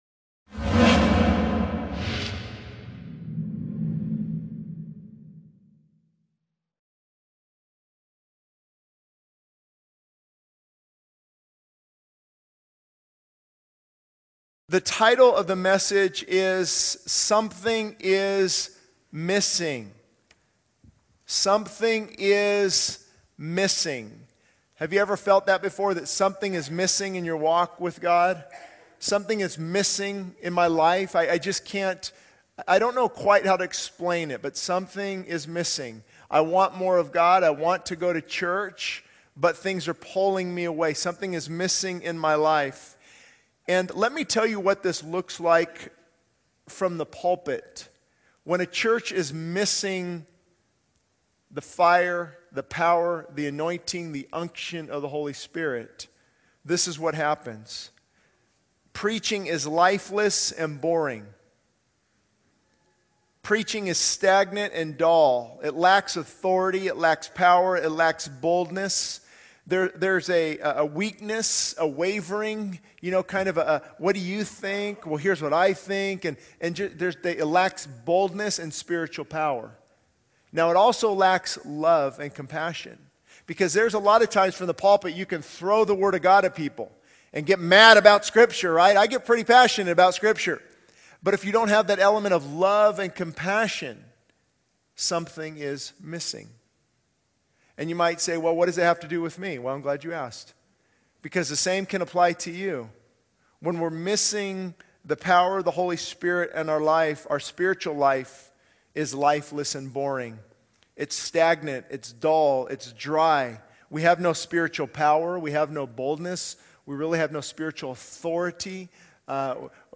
The sermon calls for a revival of the Holy Spirit's presence in both individual lives and the church.